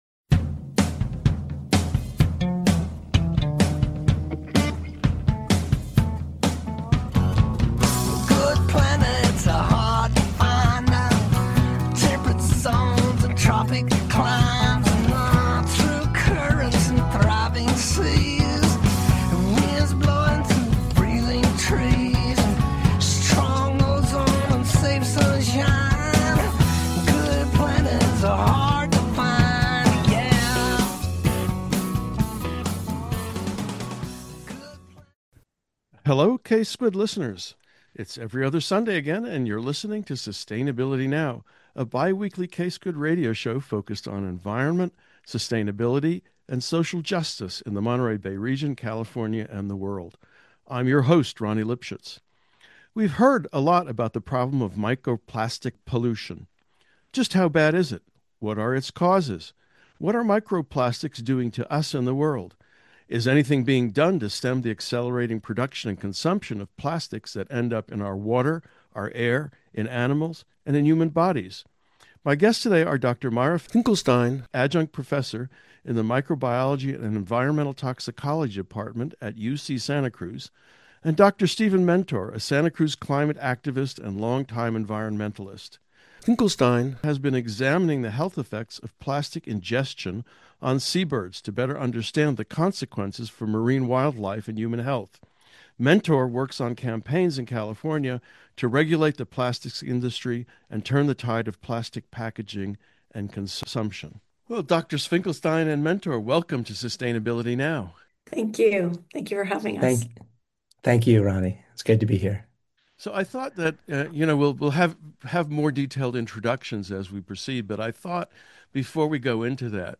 conversation about microplastics